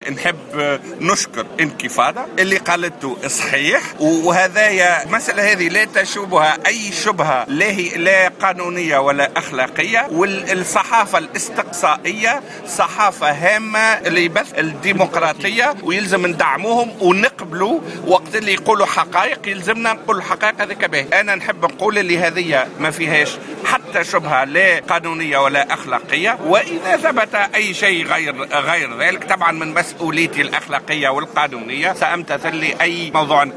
وأكد في تصريحات لـ "الجوهرة اف أم" على هامش حضوره المهرجان العربي للإذاعة والتلفزيون بالحمامات، أن ما نشره موقع انكيفادا صحيح، مستدركا بالقول إن علاقته بوثائق بنما لا تشوبها شبهة قانونية وأخلاقية، بحسب تعبيره.